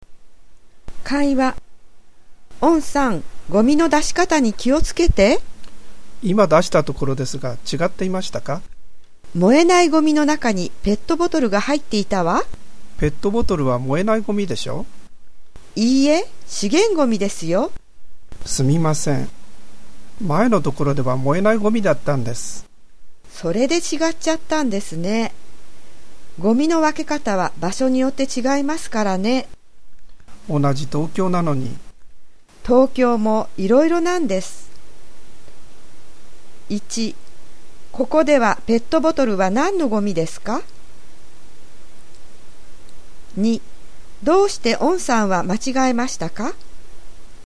【会話】(conversation)